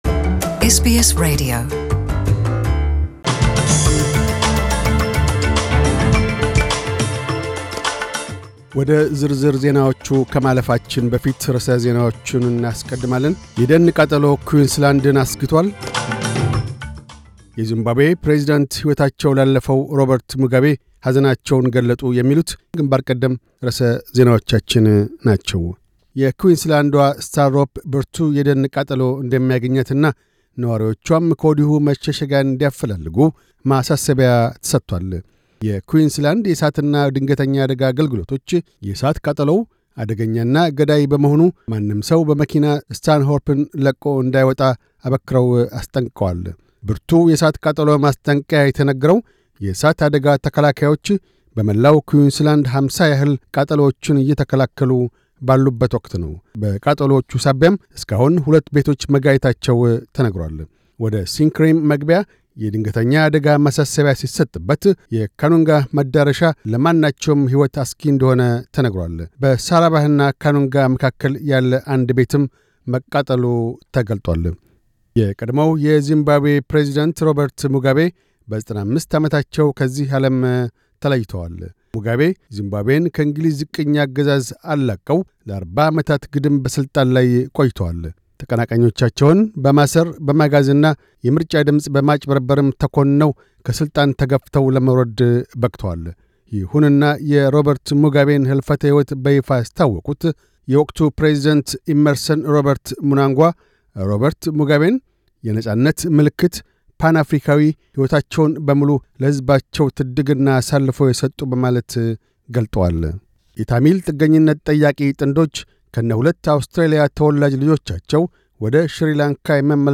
News Bulletin 0609